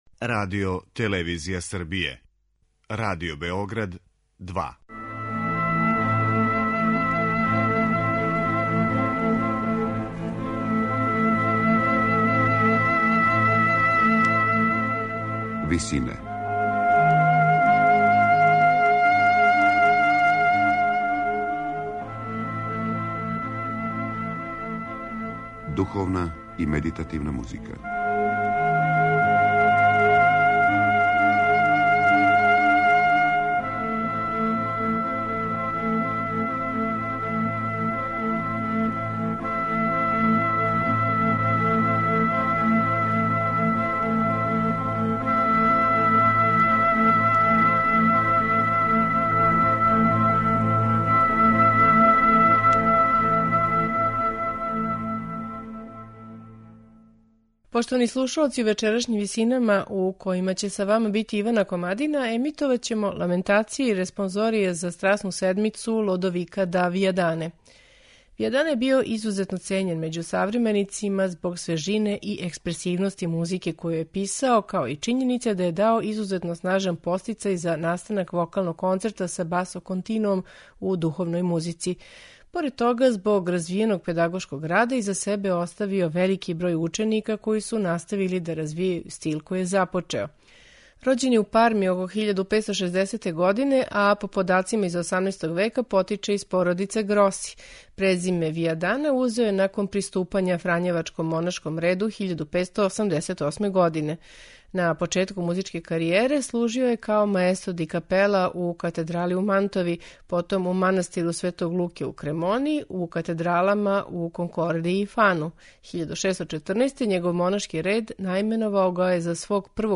контра-алт
тенор и контра-тенор
баритон
бас
у ВИСИНАМА представљамо медитативне и духовне композиције аутора свих конфесија и епоха.